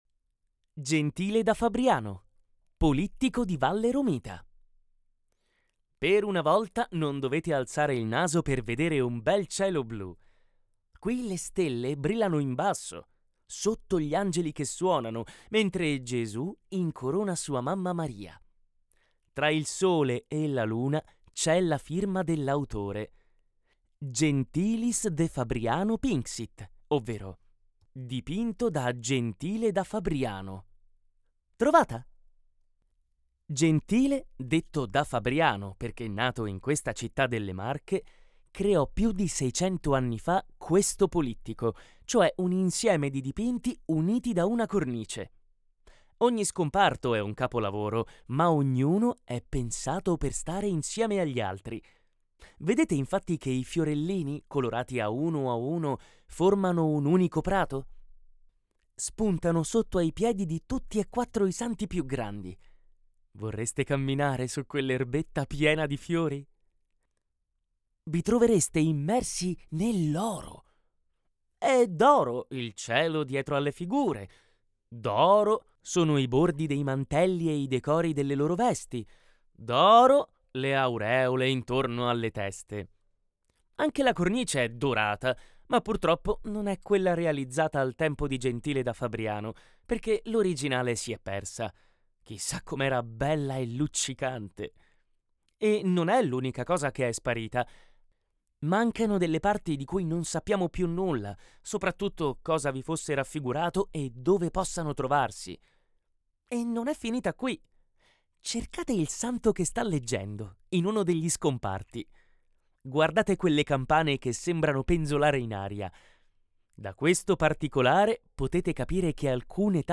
Audioguida "Brera in famiglia"